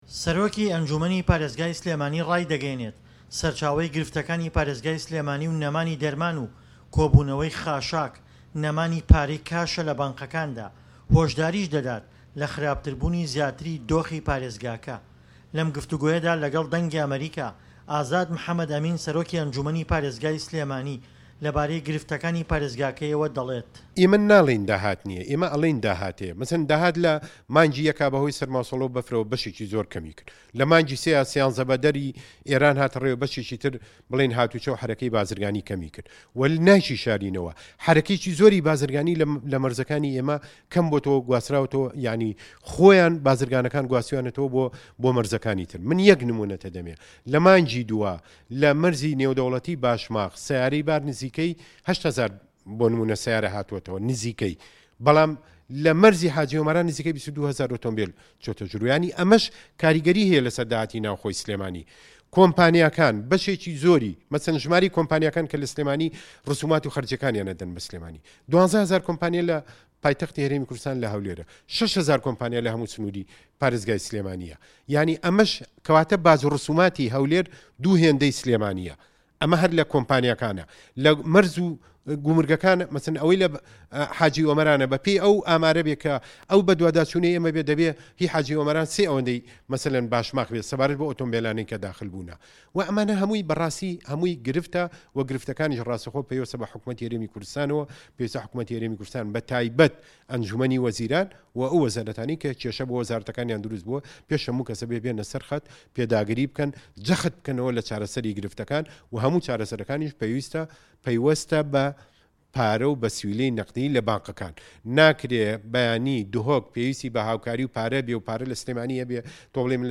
سەرۆکی ئەنجومەنی پارێزگای سلێمانی ڕایدەگەیەنێت، سەرچاوەی گرفتەکانی پارێزگای سلێمانی و نەمانی دەرمان و کۆبوونەوەی خۆڵ و خاشاک، نەمانی پارەی کاشە لە بانکەکاندا، هۆشداریش دەدات لە خراپتربوونی زیاتری دۆخەکە. لەم گفتووگۆیەدا لەگەڵ دەنگی ئەمەریکا، ئازاد محەمەد ئەمین سەرۆکی ئەنجومەنی پارێزگای سلێمانی...